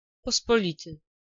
Ääntäminen
IPA : /ˈkɑm.ən/